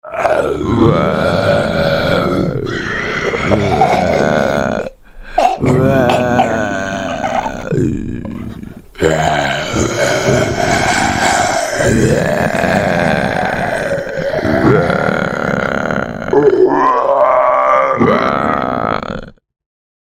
Звуки зомби